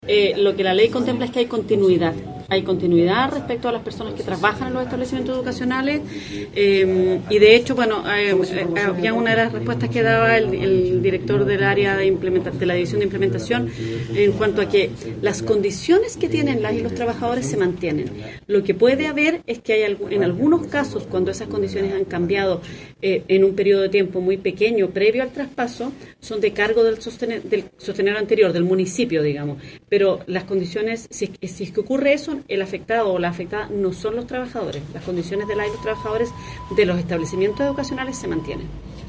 Puntualizó la subsecretaria que los funcionarios de los establecimientos educacionales tienen continuidad como también sus condiciones salariales y laborales.